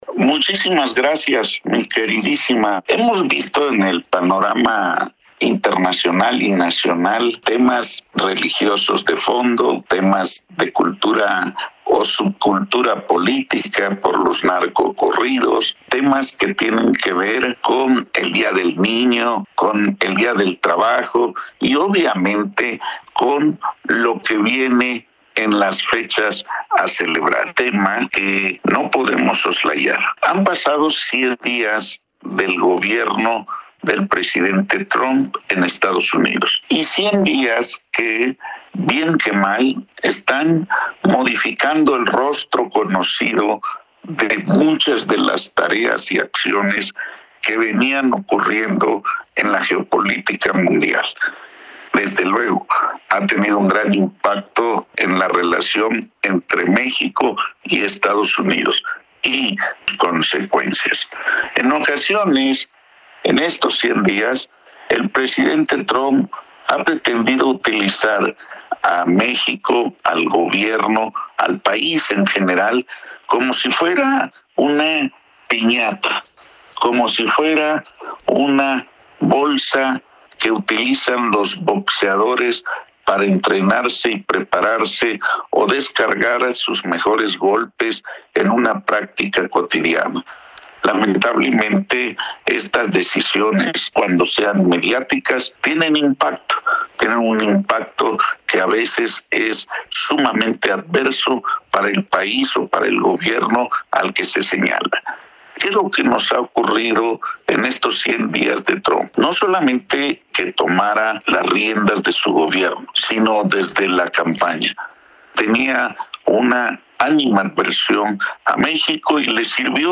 Los invitamos a escuchar en su voz, el desglose que realiza el también catedrático universitario, especialista en Seguridad Nacional y escritor.